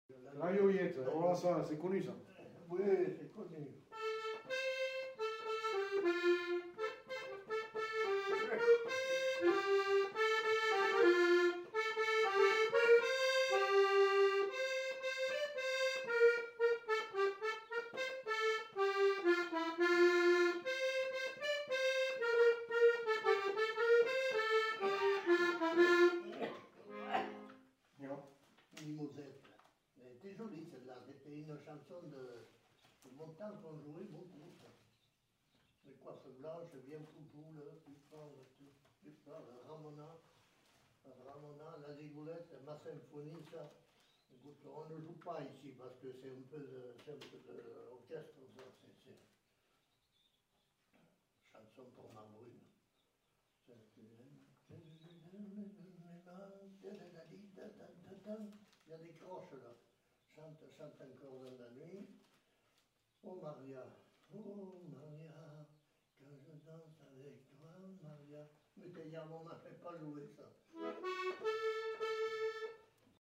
Aire culturelle : Quercy
Lieu : Chasteaux
Genre : morceau instrumental
Instrument de musique : accordéon chromatique
Danse : marche (danse)
Notes consultables : Enumération de titres de partitions en fin de séquence.